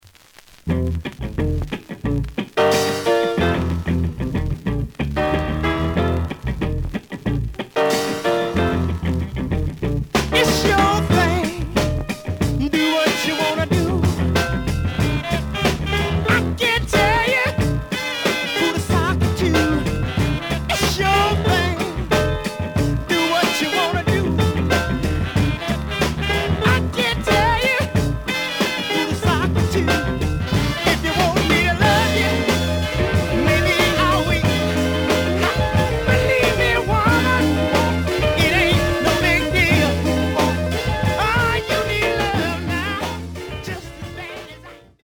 The audio sample is recorded from the actual item.
●Format: 7 inch
●Genre: Funk, 60's Funk